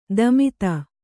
♪ damita